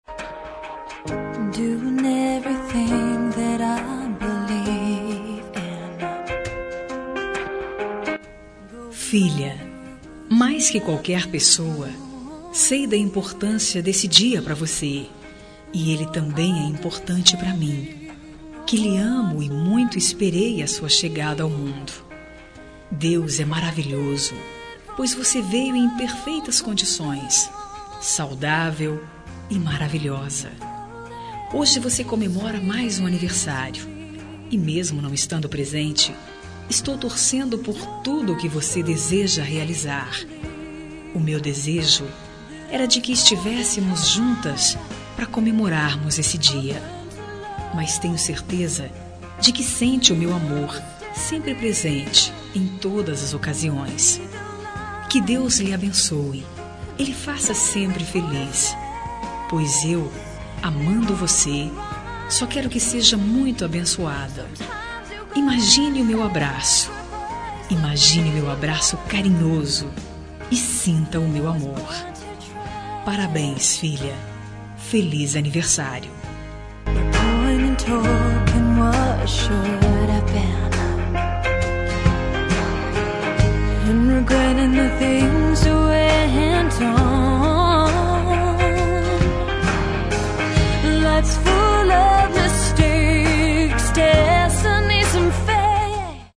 Aniversário de Filha – Voz Feminina – Cód: 5306